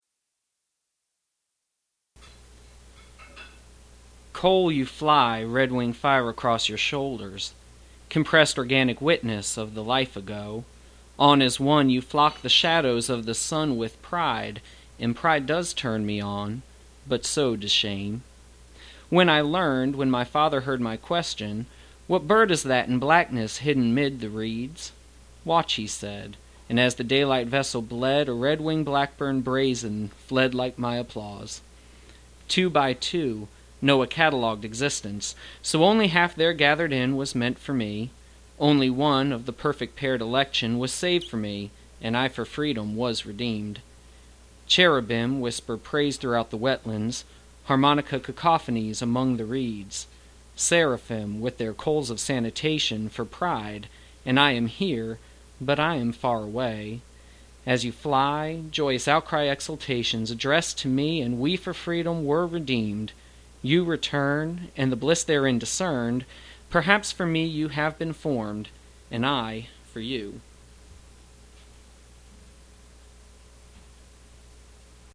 recite his poetry